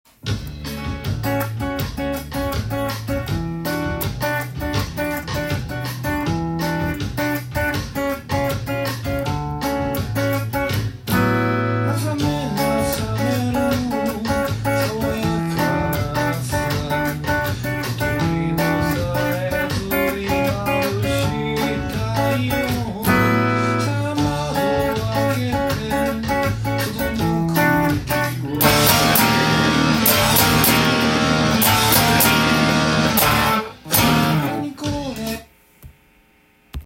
音源にあわせて譜面通り弾いてみました
始まり、ギターもボサノバの軽やかなおしゃれコードです。
１６分の裏の連続したリズムが続き
４和音のボサノバ独特の雰囲気です。